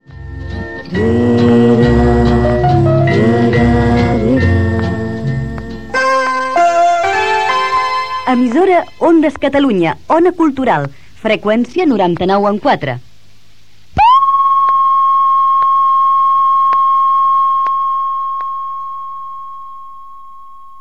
Identificació de l'emissora, amb el nom de l'empresa propietària (Ondas Cataluña) i to de prova.
FM